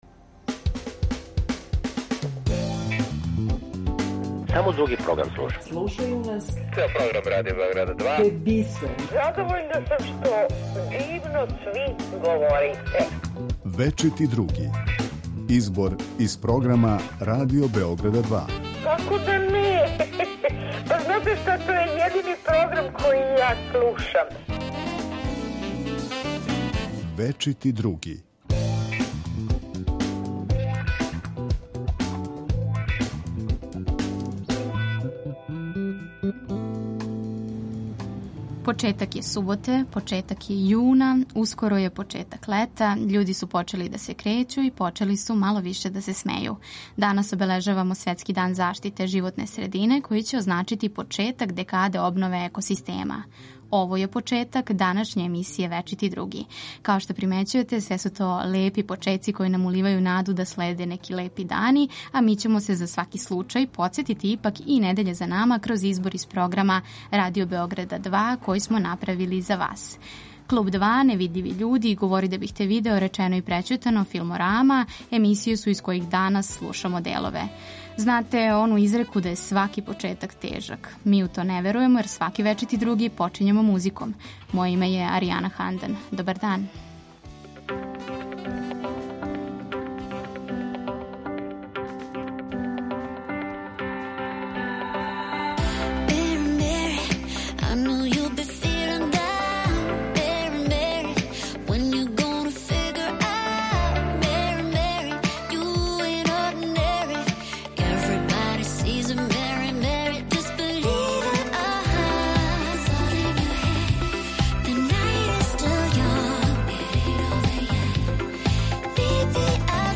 У недељном избору из програма Радио Београда 2 за вас издвајамо делове из емисија Клуб 2, Невидљиви људи, Говори да бих те видео, Речено и прећутано, Филморама, Радар...
Осим тога, чућете и друге госте Радио Београда 2. Поред тога што издвајамо делове из прошлонедељног програма, најавићемо и неке од наредних емисија на Радио Београду 2.